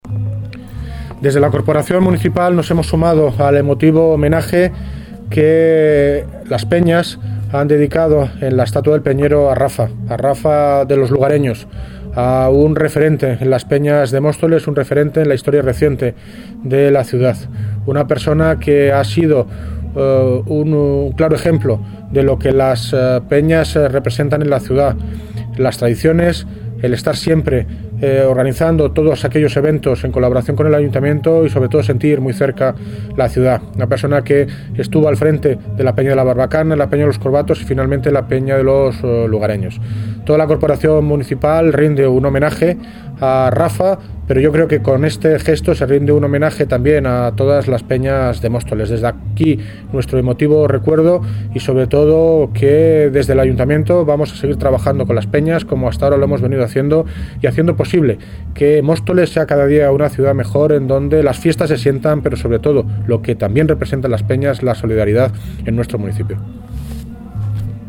David Lucas (Alcalde de Móstoles)